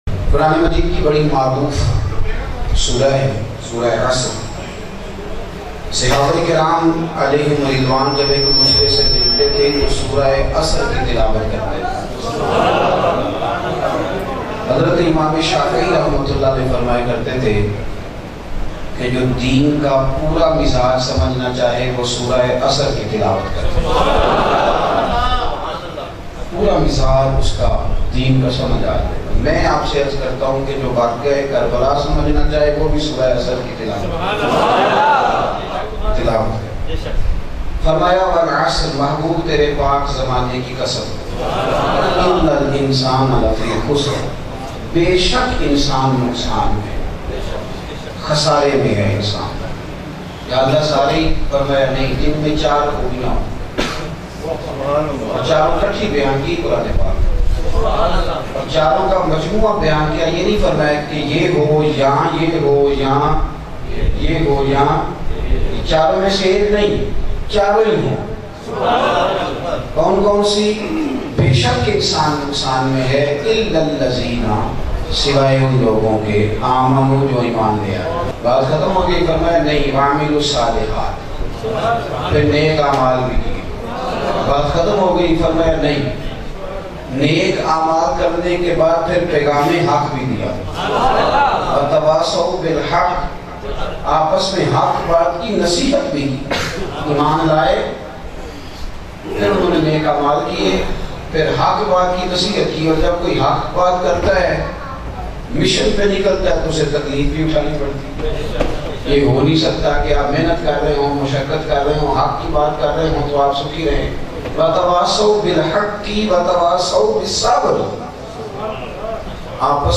Mehfilein Karwany Walon Say Hazrat Sahib Ka Shikwa Bayan MP3